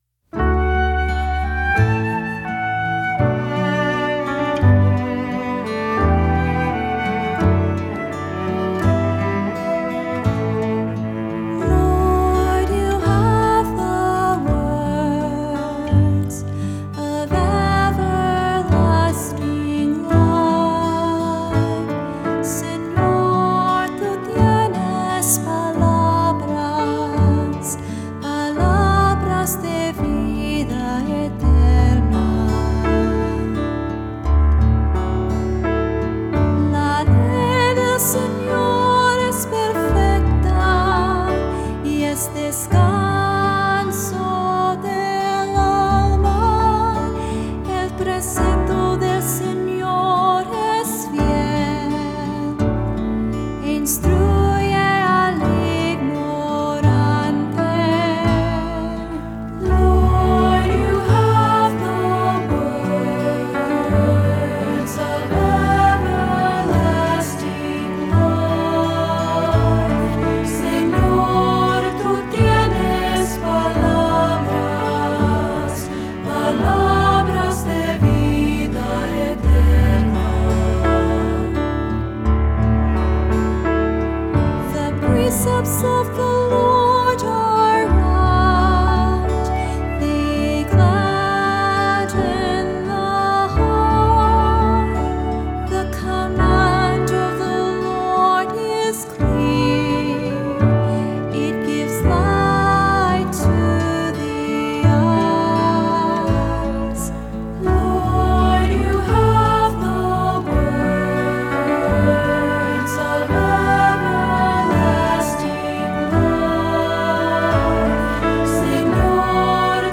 Voicing: Three-part mixed; Cantor; Assembly